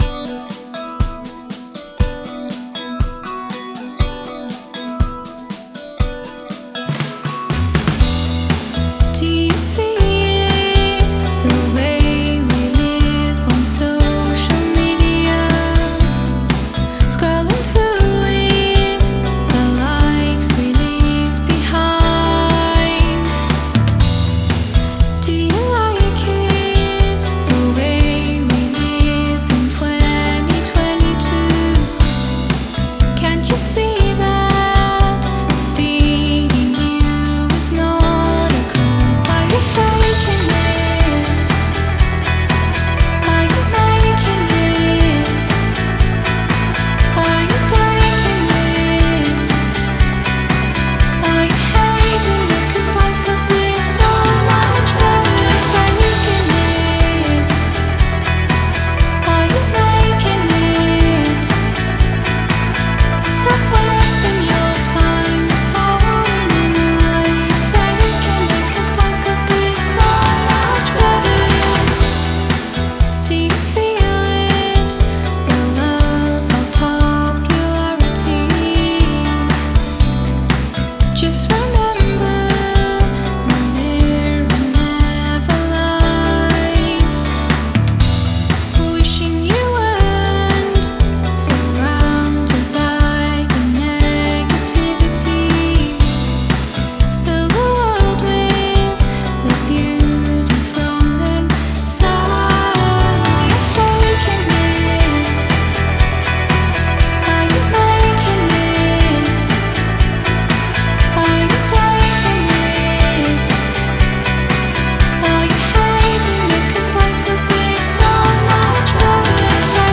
Im a singer songwriter from the north east.